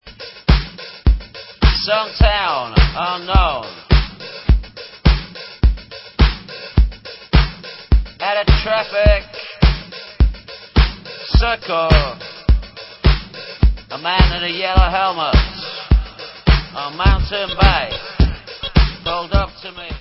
Alternativní hudba